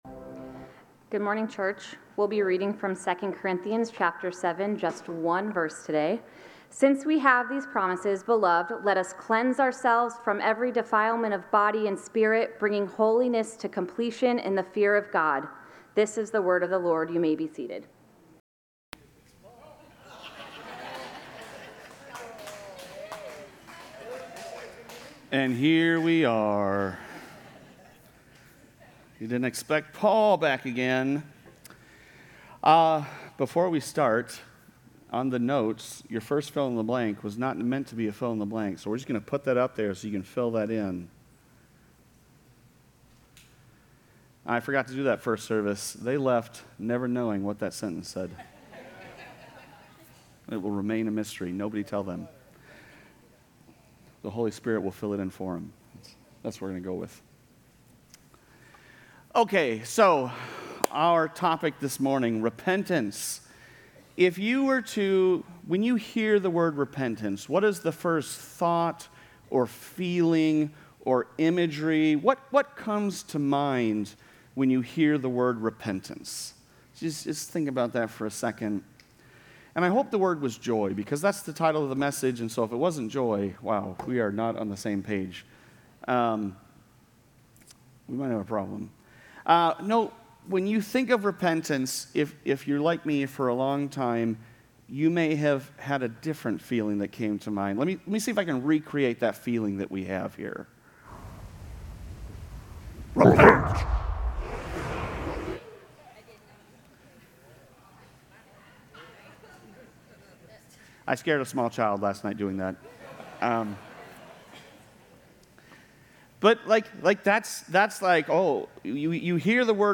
Sermon Notes: Joy in Repentance